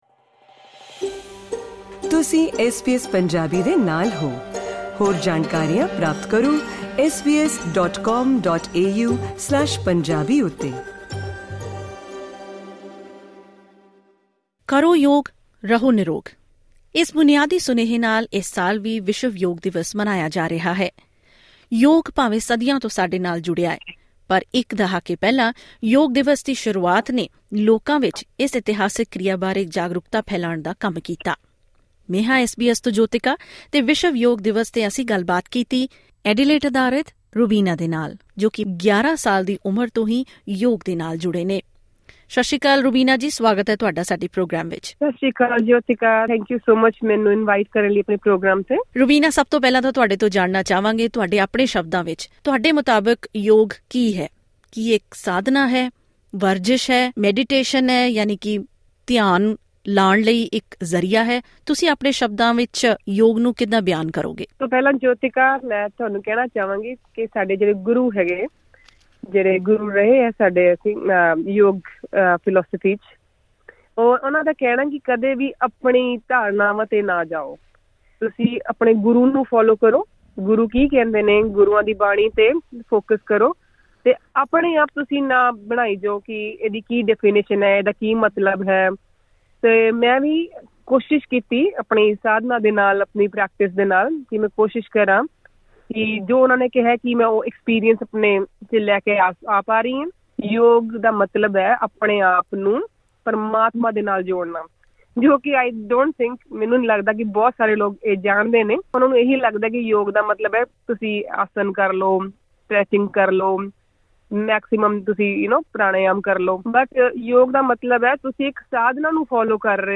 ਐਸ ਬੀ ਐਸ ਪੰਜਾਬੀ ਨਾਲ ਗੱਲਬਾਤ